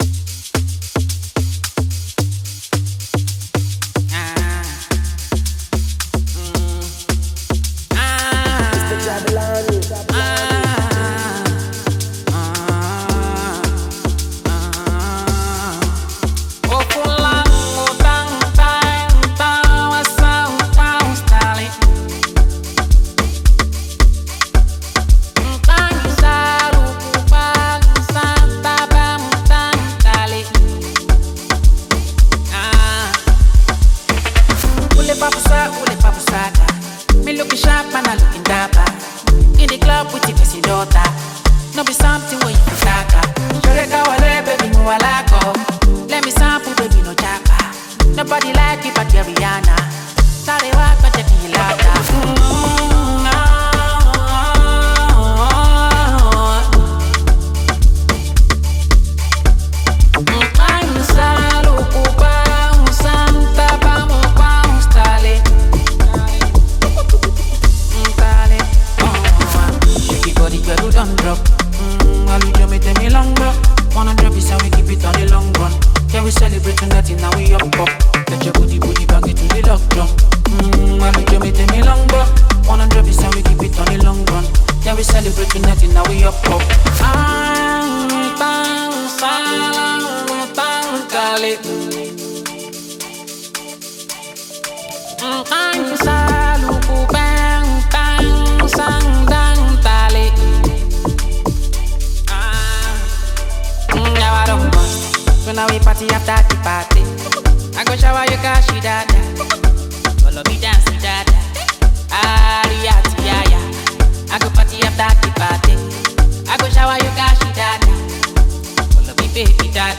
Nigerian Afro-pop artist and rapper